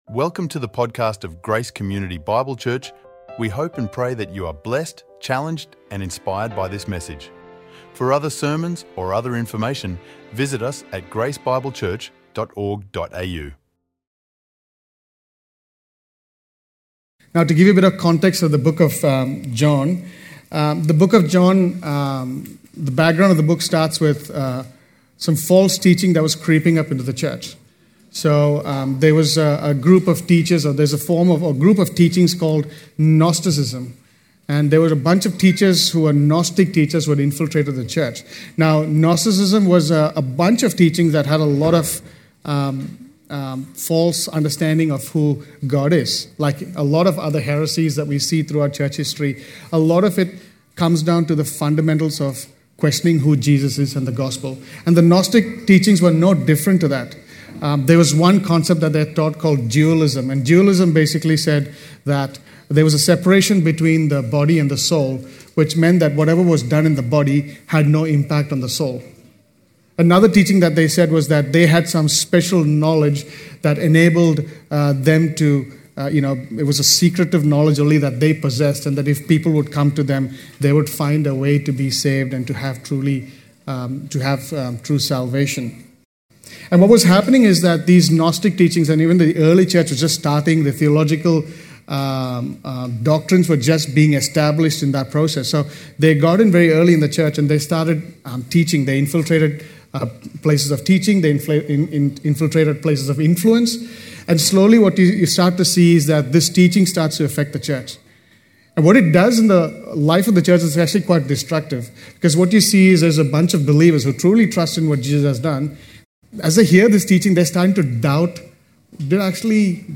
recorded live at Grace Community Bible Church, on the topic “Walking in the Light” – from 1 John 1:5-10.